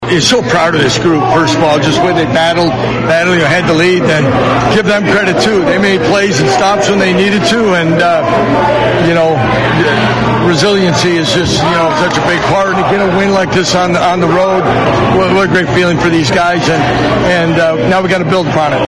Kansas Coach Lance Leipold after the game.